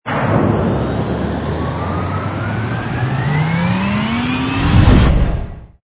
engine_br_cruise_start.wav